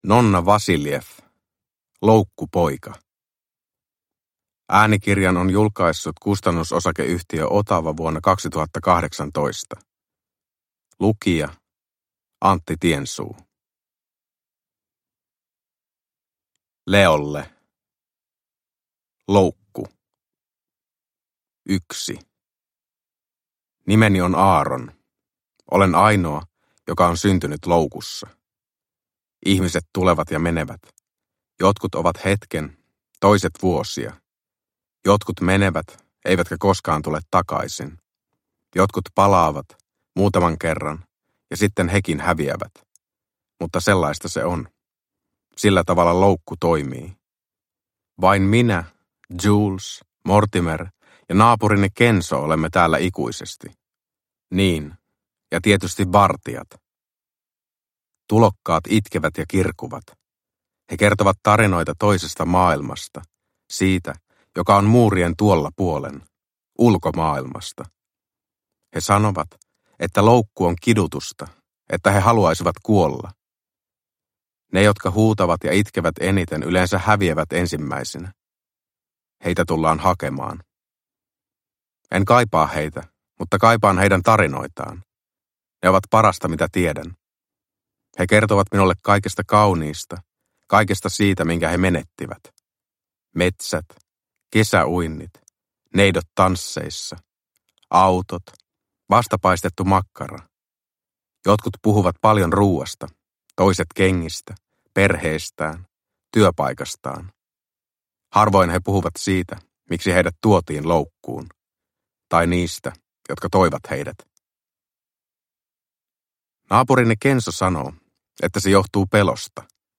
Loukkupoika – Ljudbok – Laddas ner